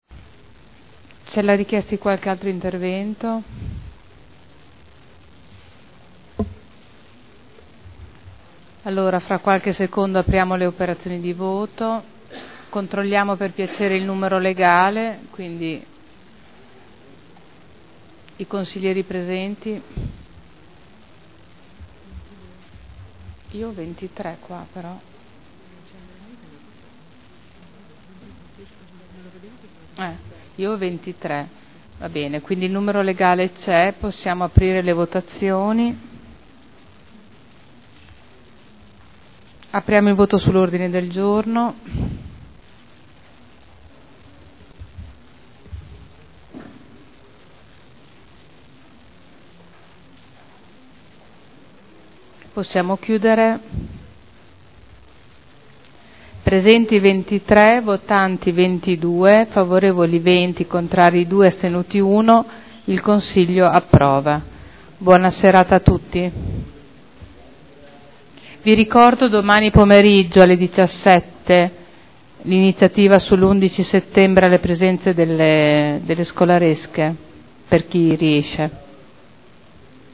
Presidente — Sito Audio Consiglio Comunale
Presidente